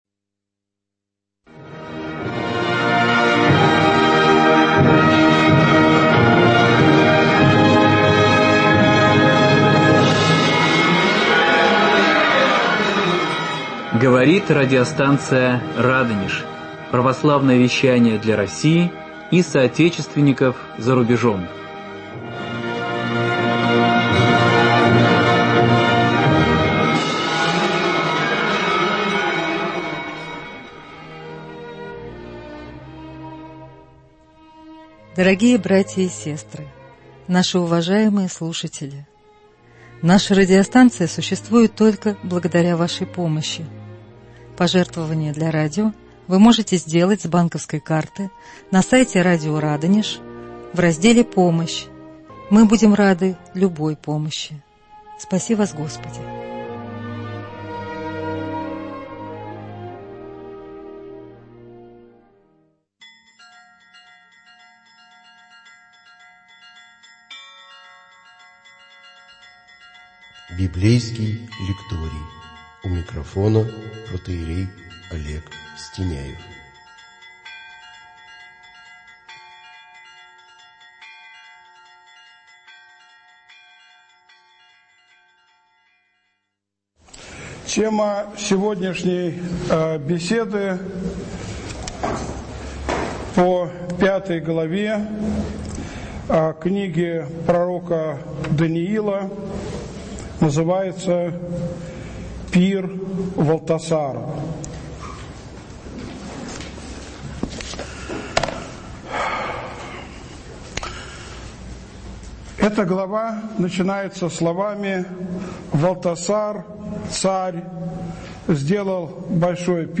Беседы на книгу пророка Даниила.